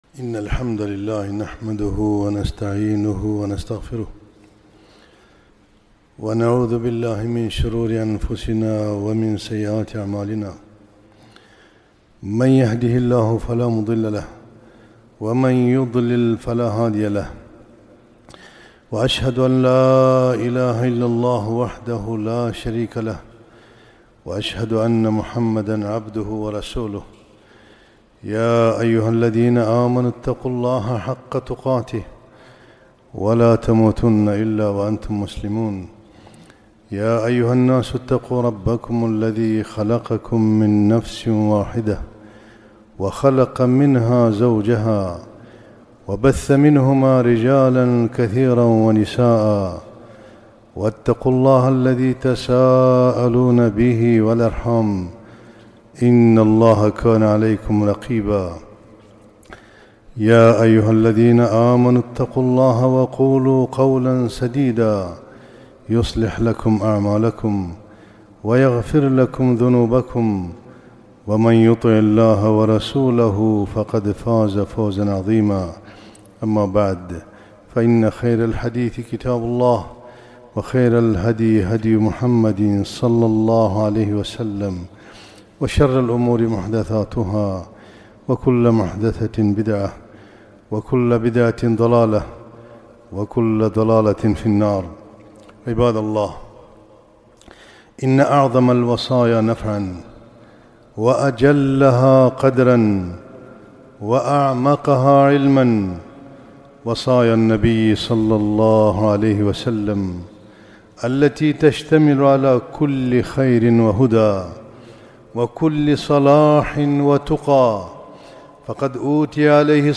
خطبة - لا تغضب